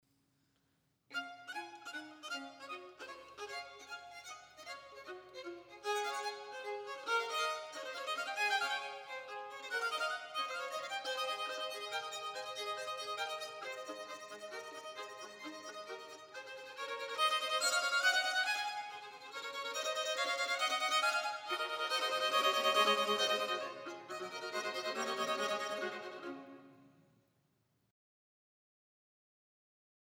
für zwei Violinen